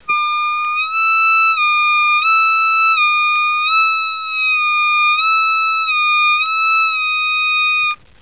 Bend8 Blow8 Bend8 Blow8
bend8blow8.wav